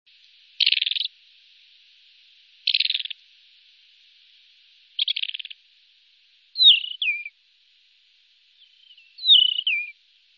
głosy innych białorzytek